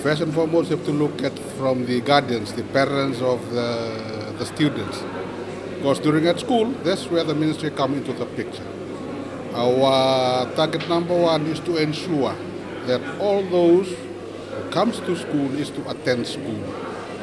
Assistant Education Minister Iliesa Vanawalu says these issues can be resolved through a collaborative approach and positive relationships between students and the community at large.